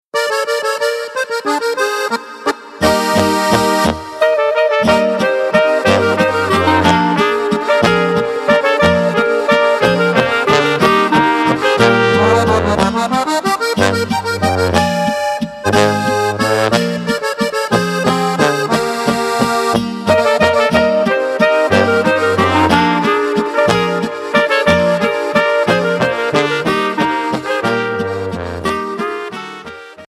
Walzer